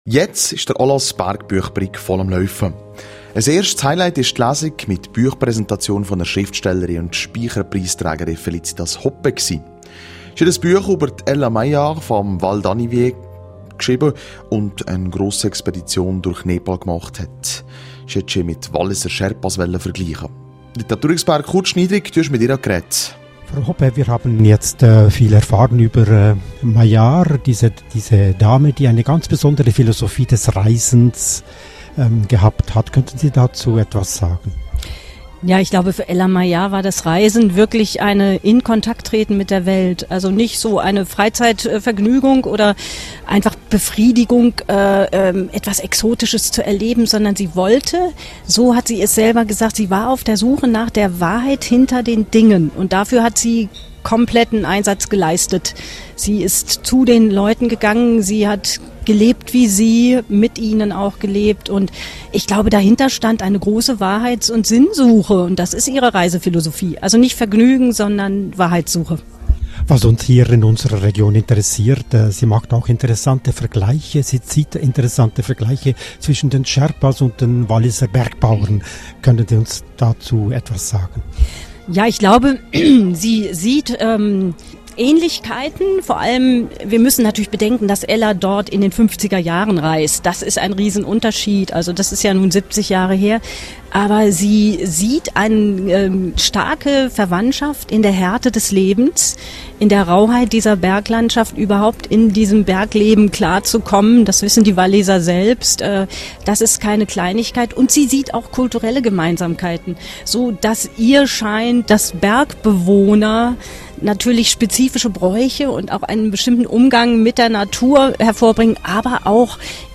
Die Spycherpreisträgerin am Multimediafestival